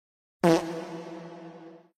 Fart reverb